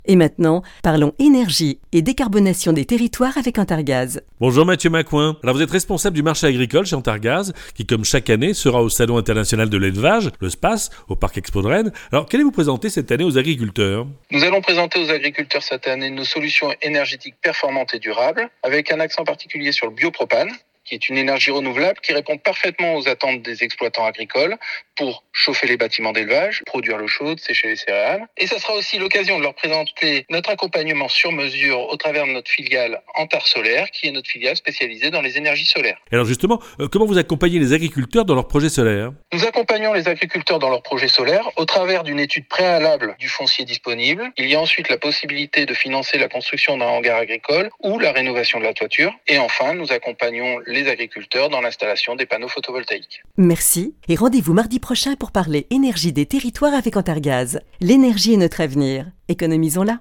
Événement Interview
Antargaz est présent au salon international de l'élevage SPACE au Parc Expo de Rennes.